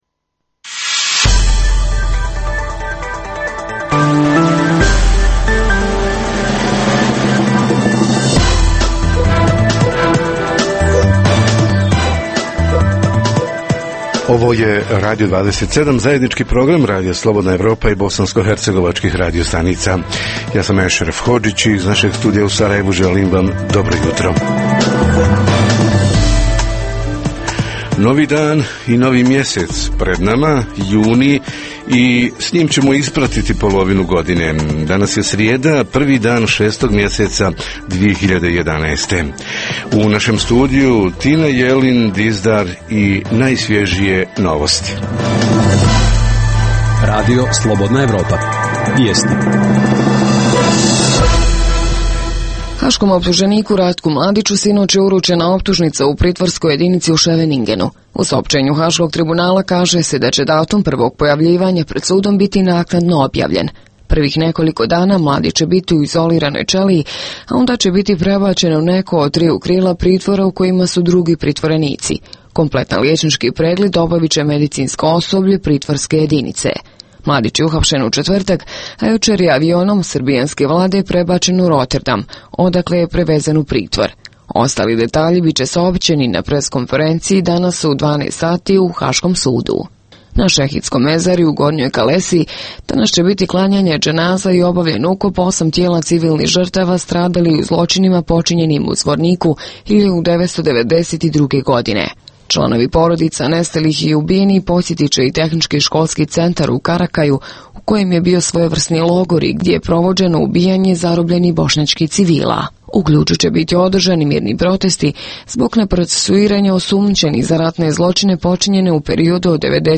Govorimo o povratku izbjeglica i raseljenih lica - šta, kako i koliko za njegovu održivost? Poslušajte informacije, teme i analize o dešavanjima u BiH i regionu, a reporteri iz cijele BiH javljaju o najaktuelnijim događajima u njihovim sredinama.